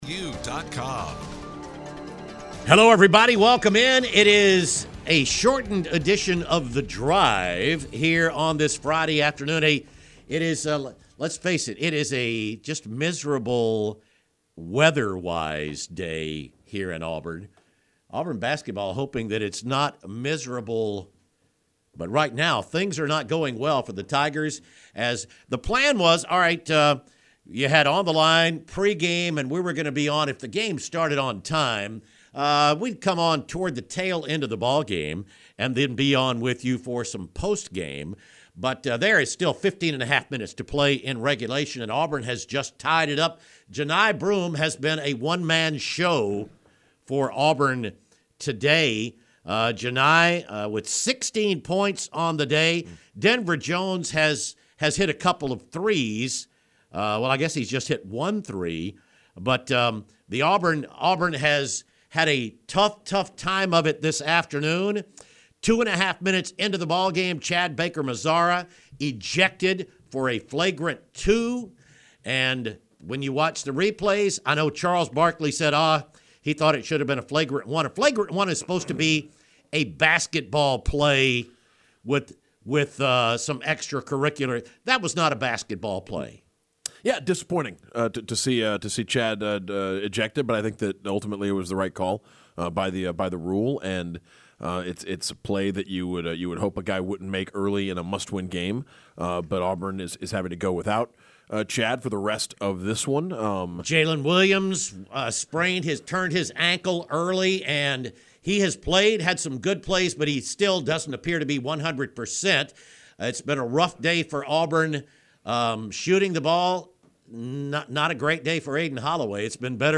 THE GUYS REACT LIVE TO THE 2ND HALF OF AUBURN/YALE